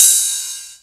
43_04_ride.wav